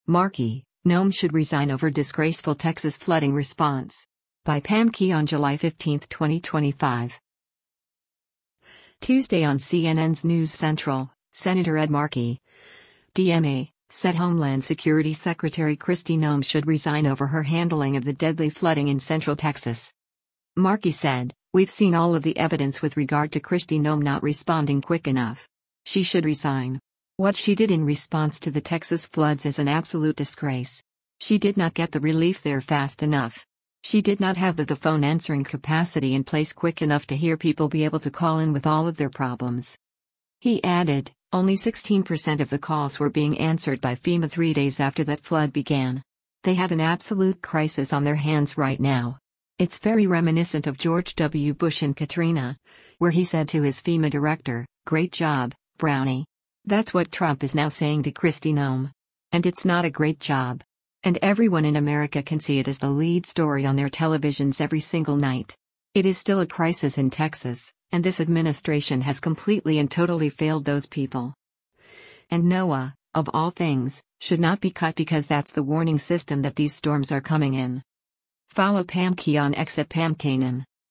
Tuesday on CNN’s “News Central,” Sen. Ed Markey (D-MA) said Homeland Security Secretary Kristi Noem should resign over her handling of the deadly flooding in central Texas.